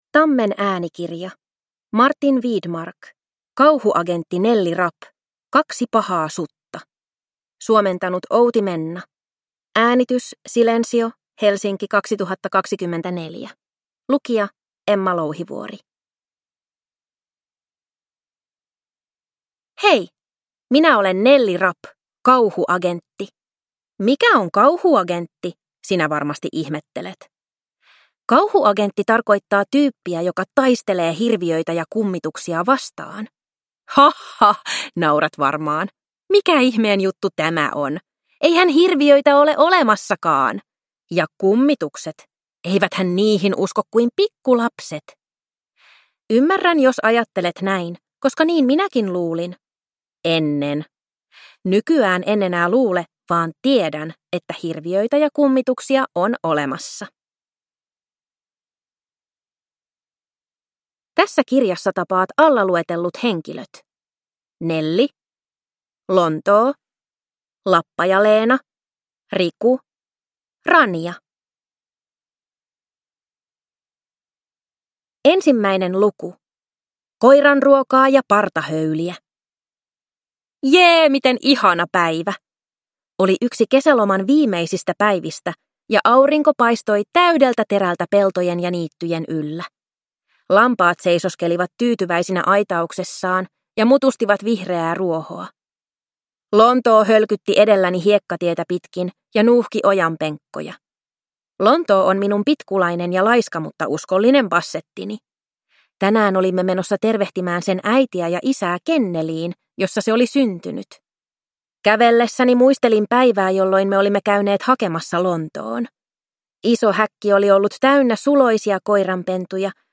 Kauhuagentti Nelli Rapp. Kaksi pahaa sutta – Ljudbok